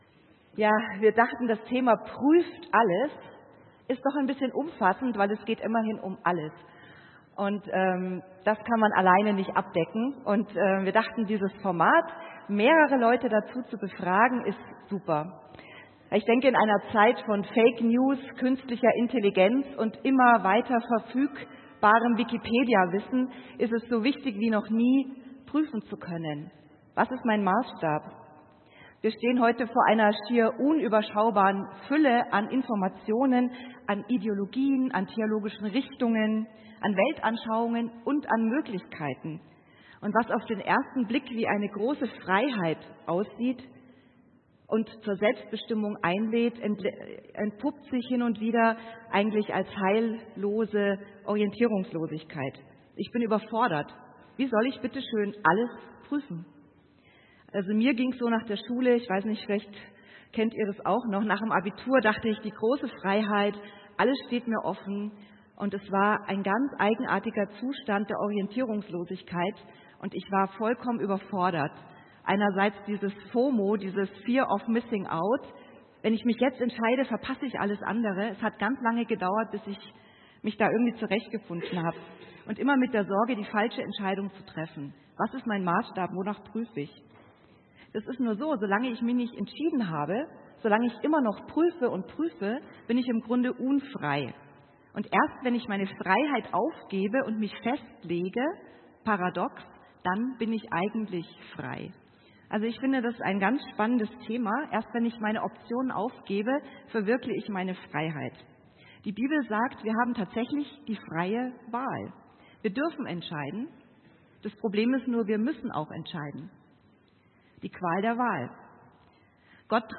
Panel Talk